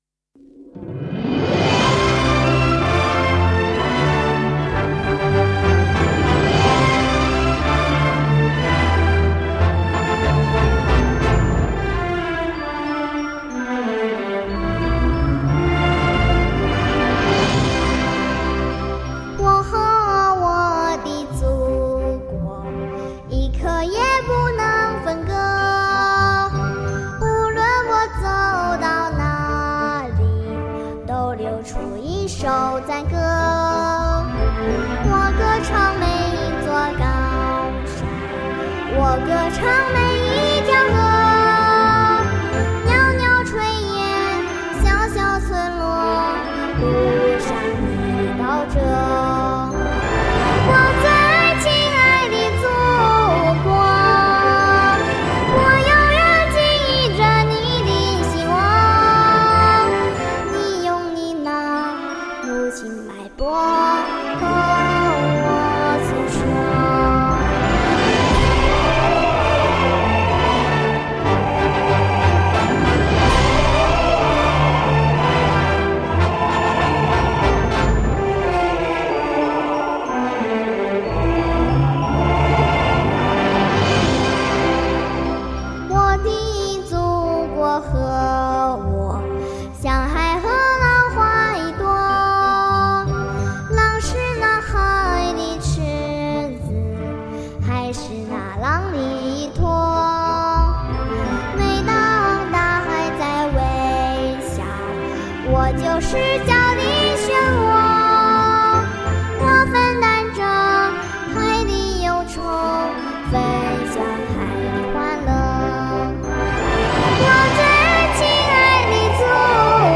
童声版